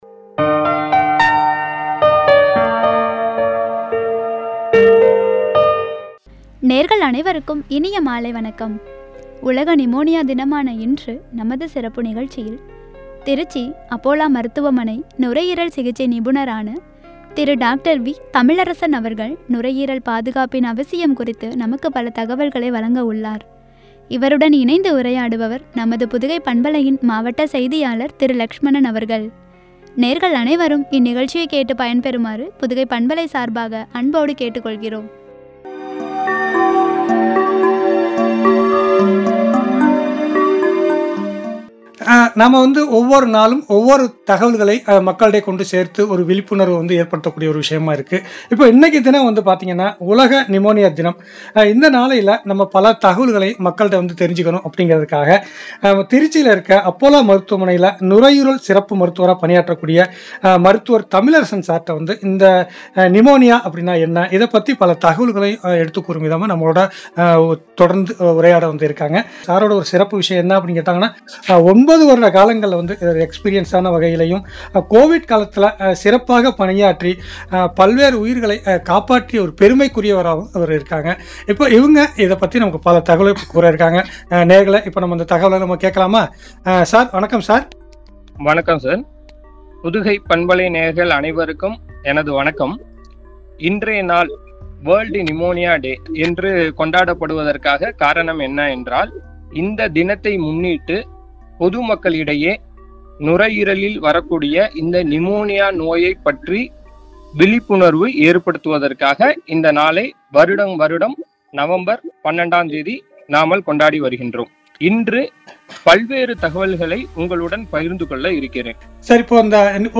முக்கியத்துவமும்” என்ற தலைப்பில் வழங்கிய உரையாடல்.